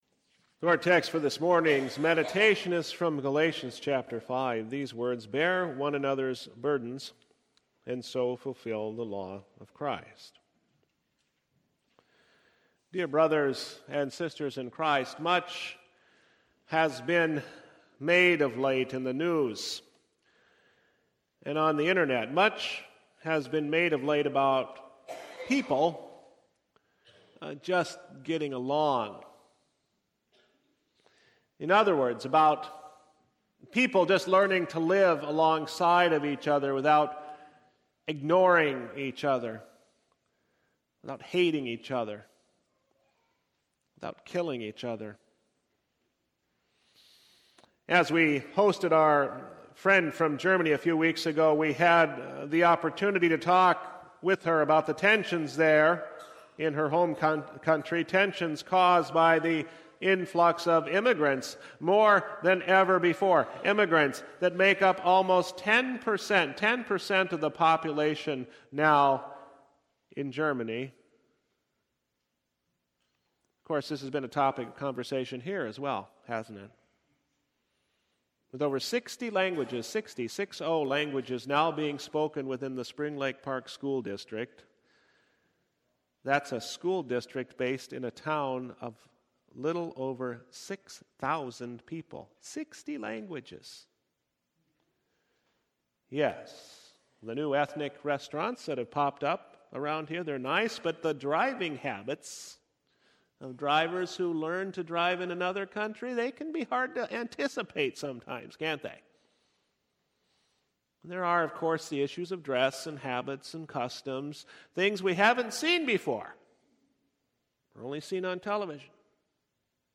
Service Type: Divine Service V